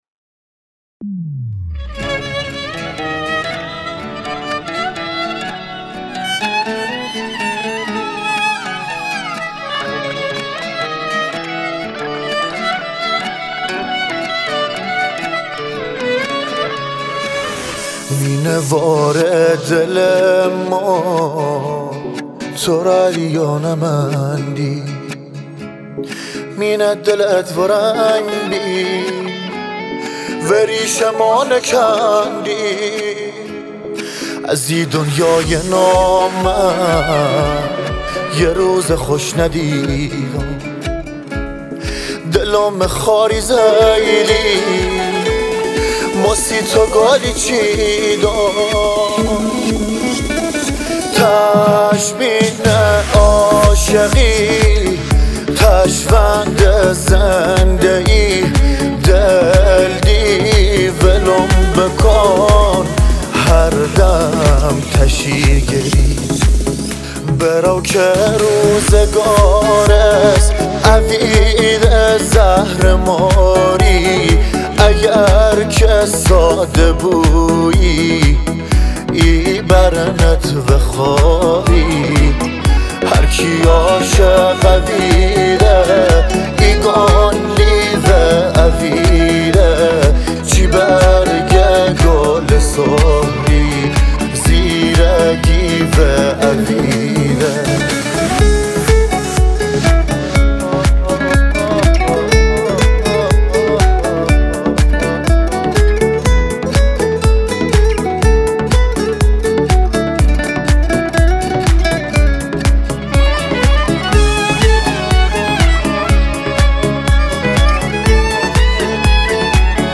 آهنگ لری
صدای خواننده باید مردونه باشه که مال شما هست